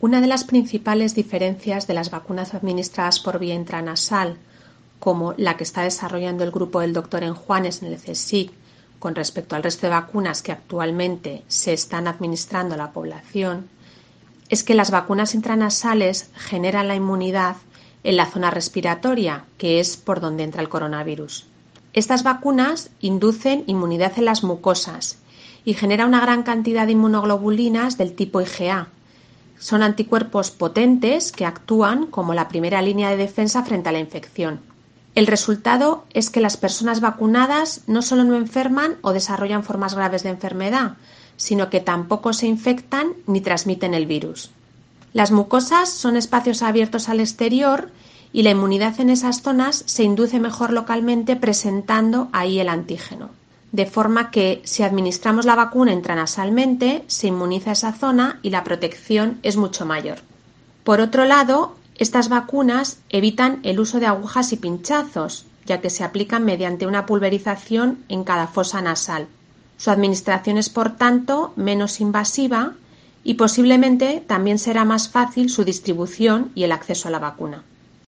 Una experta analiza y enumera las ventajas de la vacuna intranasal contra la COVID frente a las convencionales que se administran actualmente a la población